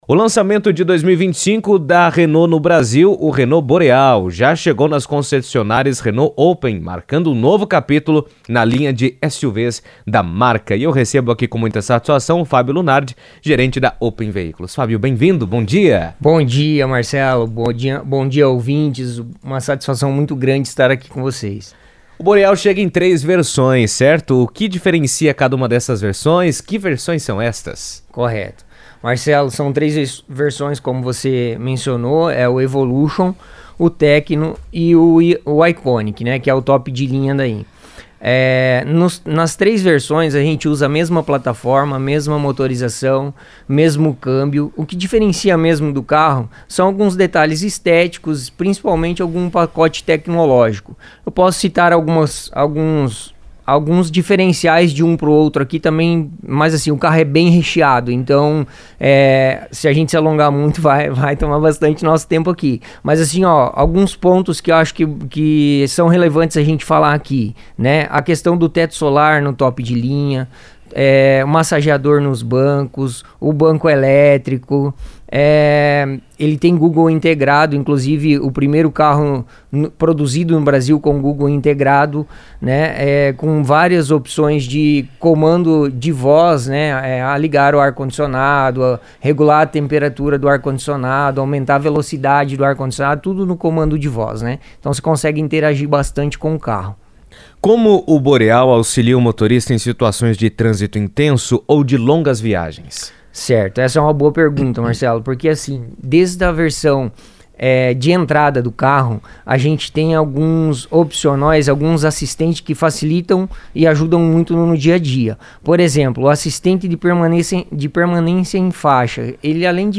O novo Renault Boreal 2025 já está disponível nas concessionárias Open Veículos, marcando a renovação da linha de SUVs da marca no Brasil. Em entrevista à CBN